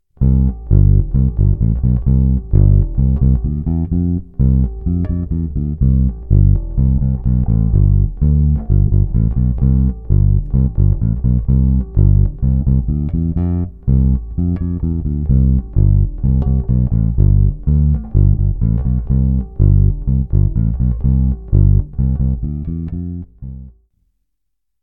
Základ jsou kvarty, a do přechodů stupnice.
Ukázka v C
C – G – C – G – A – H